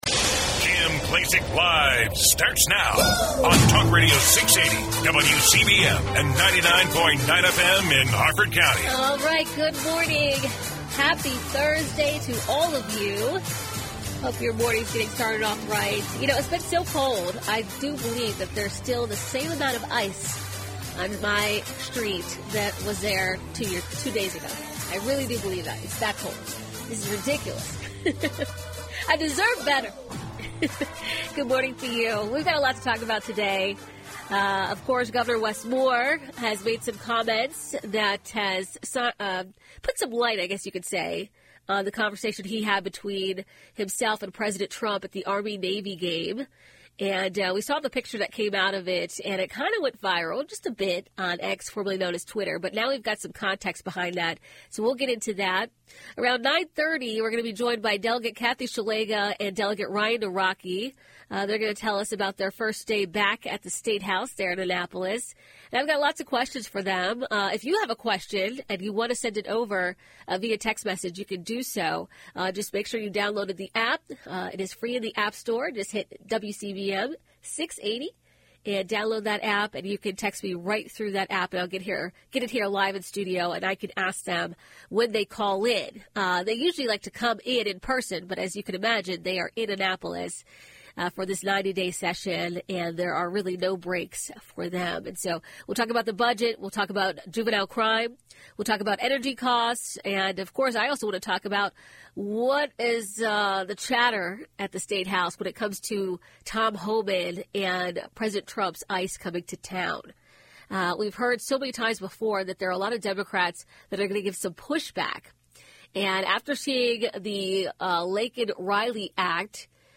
Kim Klacik is a dynamic voice who isn’t afraid to speak her mind.
Don’t miss your chance to hear from the one and only Kim Klacik live on WCBM weekdays from 9am to noon.